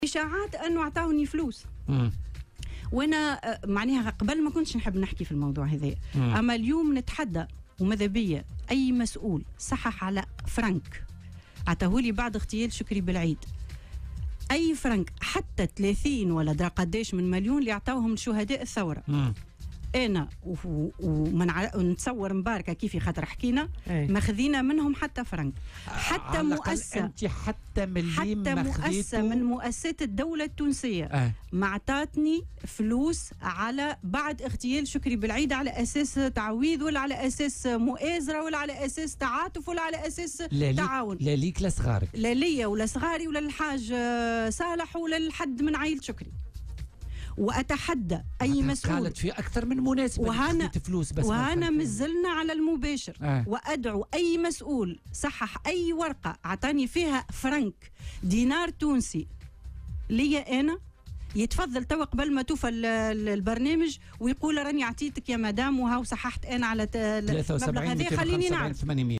ونفت في مداخلة لها اليوم على "الجوهرة أف أم" ما تم تداوله بخصوص تلقيها أو تلقي عائلة الشهيد لأموال من أي جهة على أساس "التعاطف" أو "المؤازرة" أو "التعويض"، وفق قولها.